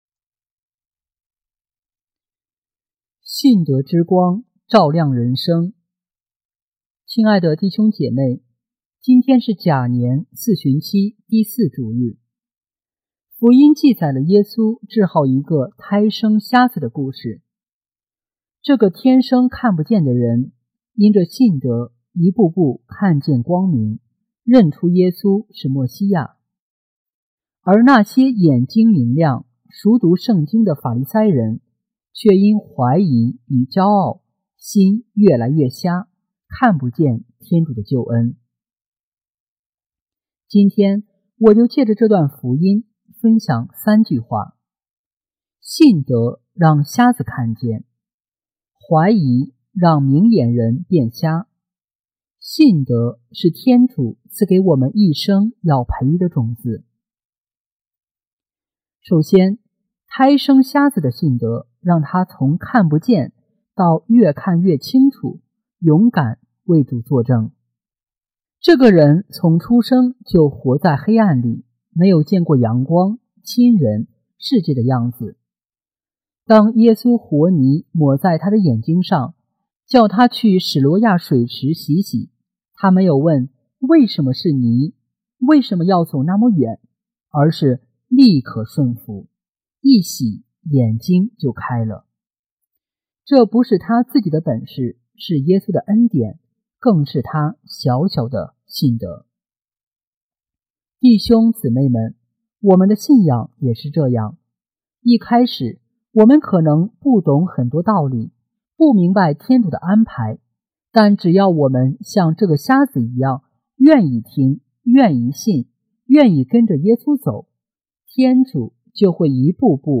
信德之光，照亮人生( 甲-四旬期第四主日讲道)